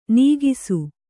♪ nīgisu